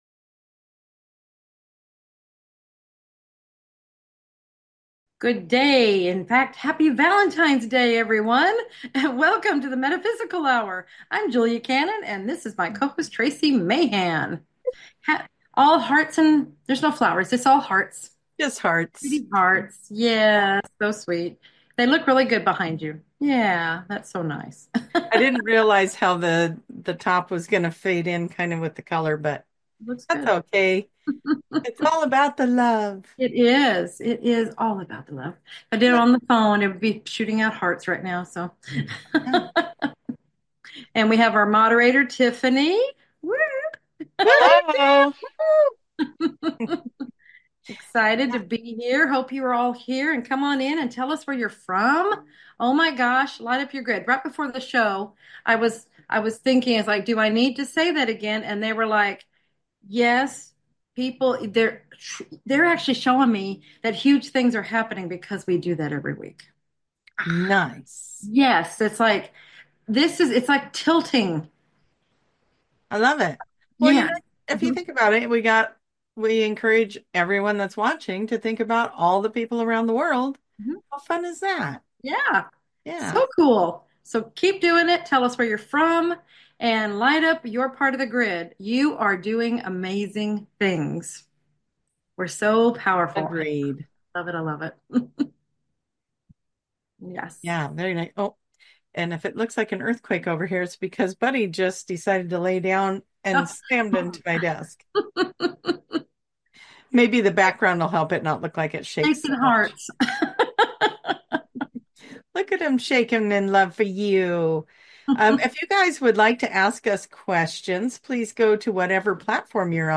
Answering viewer questions